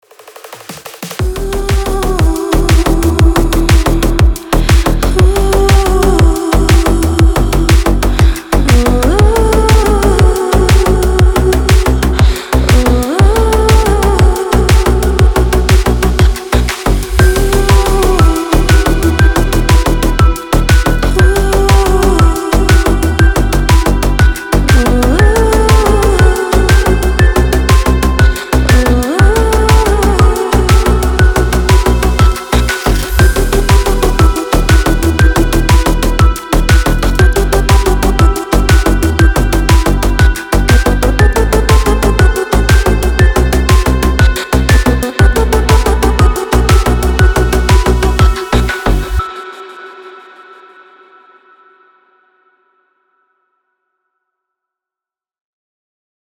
Electronic
спокойные
красивый женский голос
клубняк
эхо
Стиль: deep house